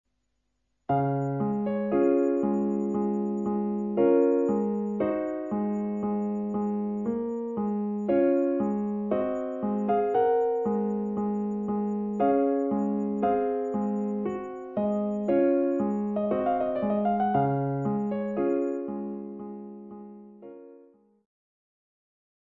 › notengetreue Wiedergabe [MP3 | 86 KB] durch das VST Grand Piano in der Stellung durch das Programm CUBASE.